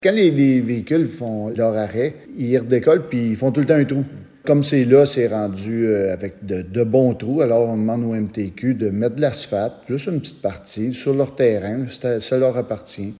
Voici les propos du maire, Steve Lefebvre :